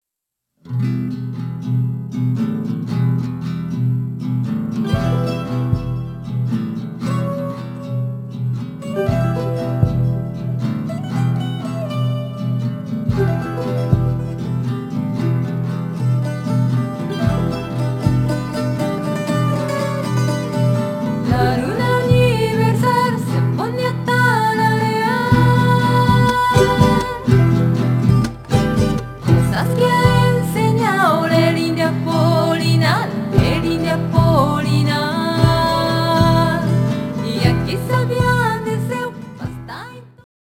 フォルクローレミュージックのバンドを組んでいます。